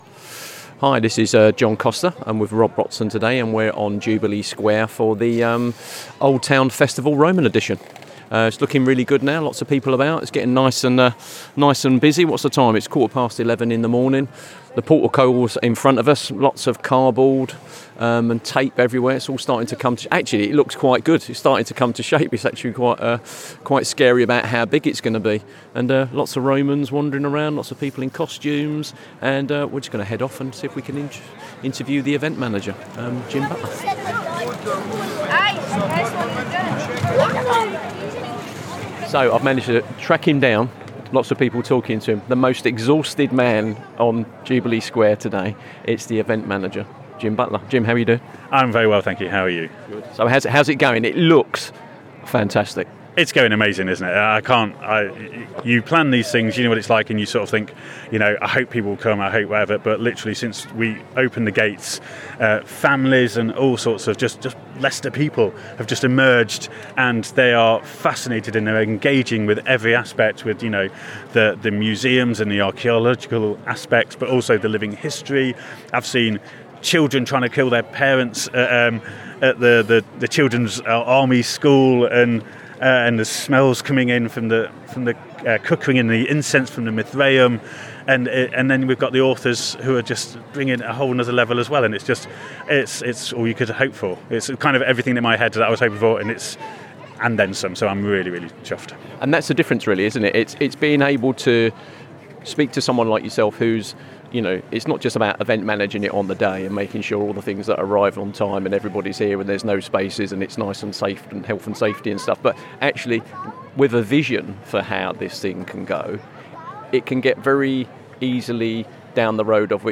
In this episode of Spotlight on Heritage from Soar Sound, we visit Jubilee Square during the Old Town Festival – Roman Edition, where Leicester’s rich Roman history was brought into the present through a series of hands-on activities, performances, and community-led projects.
Throughout the programme, we hear from re-enactors who embody the daily lives of Roman soldiers, local traders, and even Boudica herself. Their conversations offer an engaging insight into the realities of Roman Britain, highlighting both the familiar and the surprising aspects of life two thousand years ago.